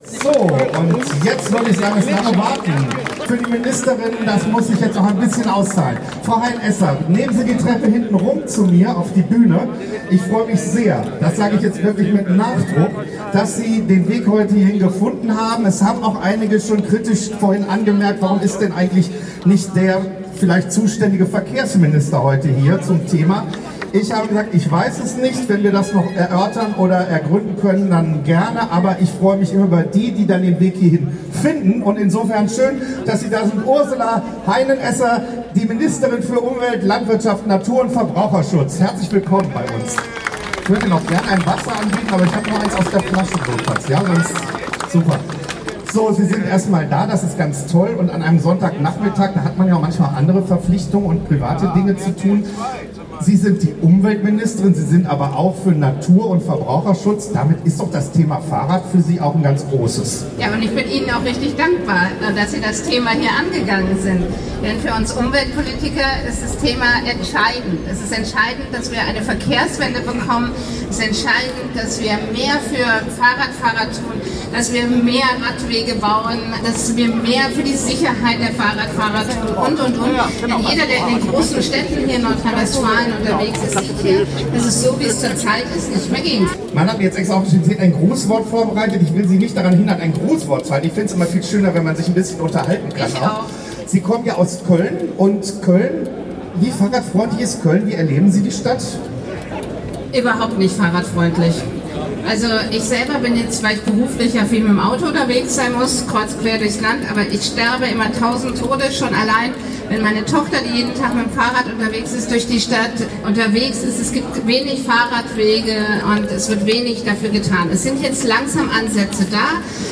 Kapitel 2: Kundgebung und Unterschriftenübergabe
Die Reden rund um das Anliegen der Volksinitiative „Aufbruch Fahrrad“
Ursula Heinen-Esser[86, 87, 88, 89] (NRW-Umweltministerin[90, 91])